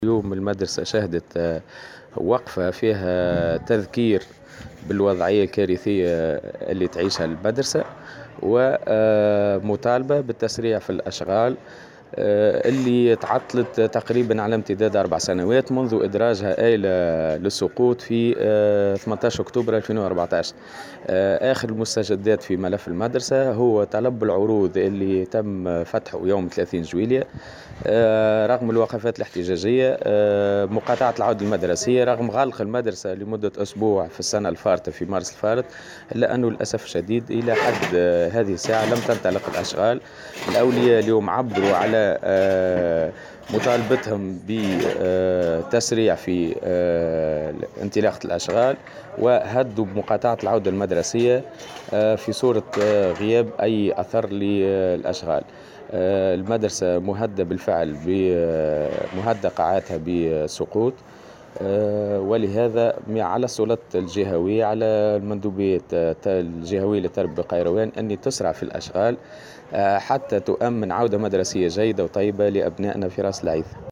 في تصريح لمراسل